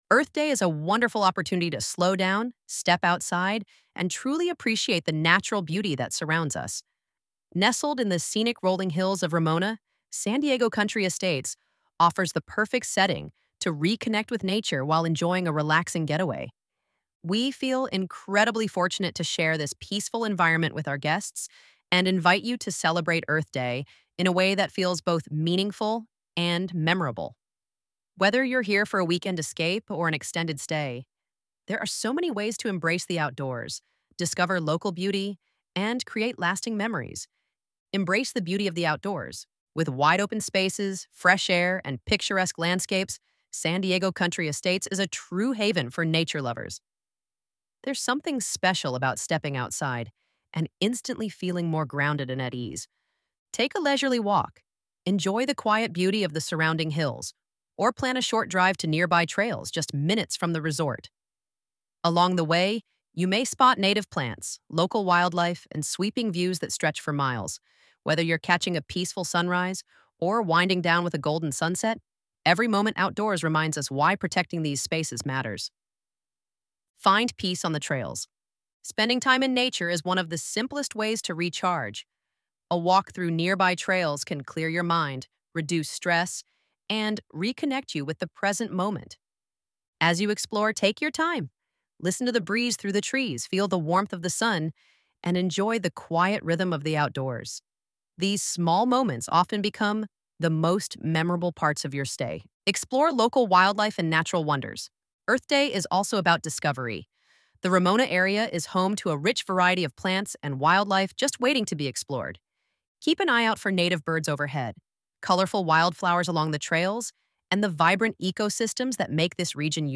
ElevenLabs_Untitled_project-8.mp3